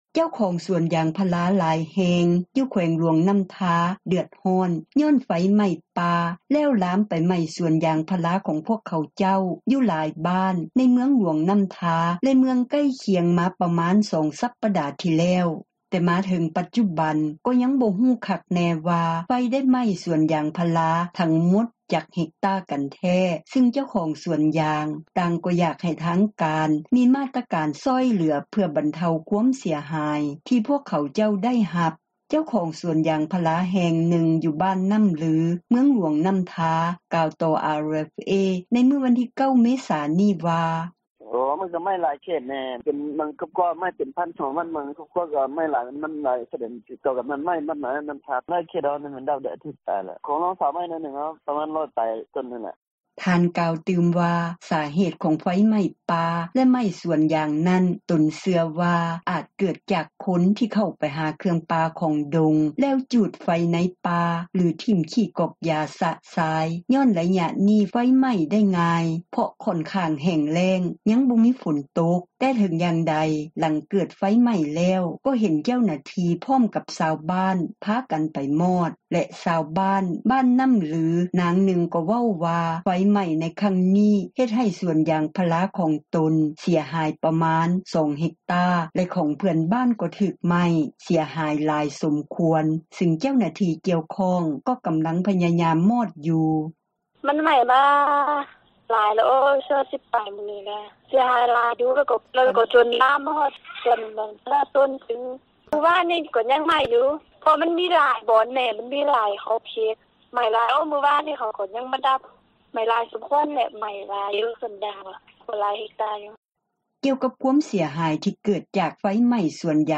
ເຈົ້າຂອງສວນຢາງພາລາ ຫລາຍແຫ່ງຢູ່ແຂວງ ຫລວງນໍ້າທາ ເດືອດຮ້ອນ ຍ້ອນໄຟໄໝ້ປ່າແລ້ວລາມໄປໄໝ້ສວນຢາງພາລາ ຂອງເຂົາເຈົ້າ ຢູ່ຫລາຍບ້ານ ໃນເມືອງຫລວງນໍ້າທາ ແລະ ເມືອງໃກ້ຄຽງມາ ປະມານ 2 ສັປດາທີ່ແລ້ວ. ແຕ່ມາເຖິງປັດຈຸບັນ ກໍຍັງບໍ່ຮູ້ຄັກແນ່ວ່າ ໄຟໄດ້ ໄໝ້ສວນຢາງພາລາ ທັງໝົດຈັກເຮັກຕາກັນແທ້ ຊຶ່ງເຈົ້າຂອງສວນຢາງ ຕ່າງກໍຢາກໃຫ້ທາງການ ມີມາຕການຊ່ອຍເຫລືອ ເພື່ອບັນເທົາ ຄວາມເສັຍຫາຍ ທີ່ຂອງພວກເຂົາເຈົ້າໄດ້ຮັບນັ້ນ. ເຈົ້າຂອງສວນຢາງພາລາແຫ່ງນຶ່ງ ຢູ່ບ້ານນໍ້າ ລື ເມືອງຫລວງນໍ້າທາ ກ່າວຕໍ່ RFA ໃນມື້ວັນທີ 9 ເມສານີ້ວ່າ:
ຊາວບ້ານບ້ານນໍ້າລື ນາງນຶ່ງກໍເວົ້າວ່າ ໄຟໄໝ້ໃນຄັ້ງນີ້ ເຮັດໃຫ້ສວນຢາງພາລາຂອງຕົນ ເສັຽຫາຍປະມານ 2 ເຮັກຕາ ແລະຂອງເພື່ອນບ້ານ ກໍຖືກໄໝ້ເສັຽຫາຍຫລາຍສົມຄວນ ຊຶ່ງທາງເຈົ້າໜ້າທີ່ ກ່ຽວຂ້ອງ ກໍກໍາລັງພຍາຍາມມອດຢູ່: